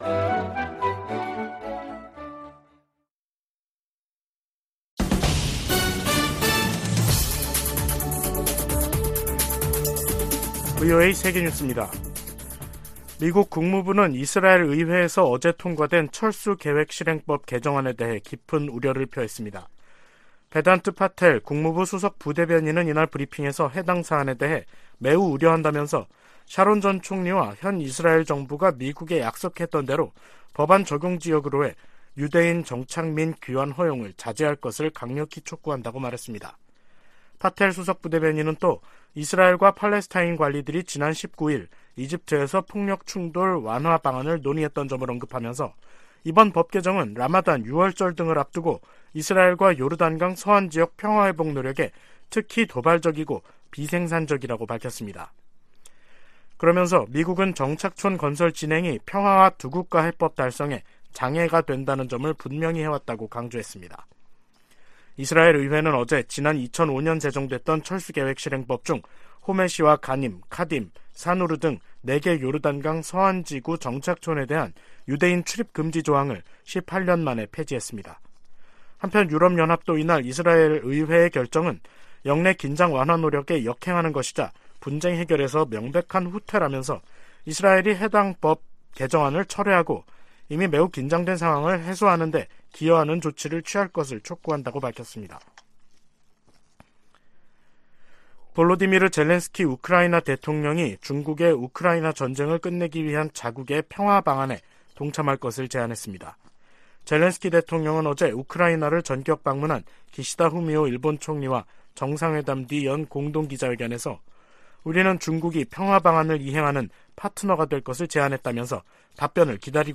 세계 뉴스와 함께 미국의 모든 것을 소개하는 '생방송 여기는 워싱턴입니다', 2023년 3월 22일 저녁 방송입니다. '지구촌 오늘'에서는 중국과 일본 정상들이 전쟁 중인 러시아와 우크라이나 방문을 각각 끝낸 이야기 살펴보고, '아메리카 나우'에서는 로스앤젤레스 교사 파업 소식 전해드리겠습니다.